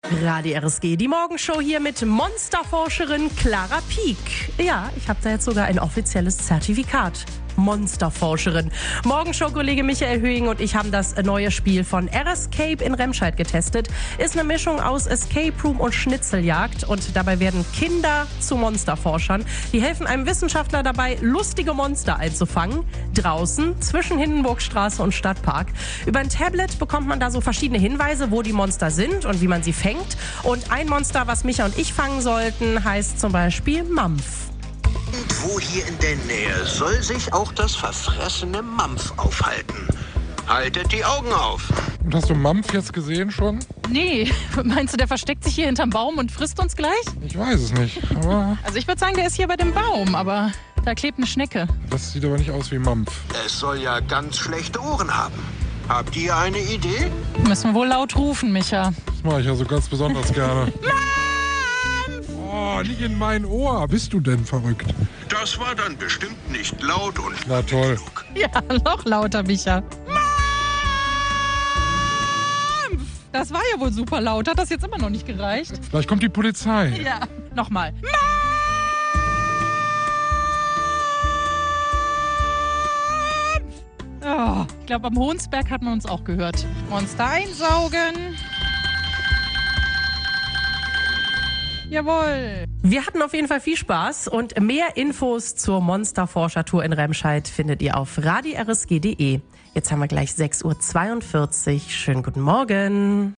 Eindrücke von der Monsterforscher-Tour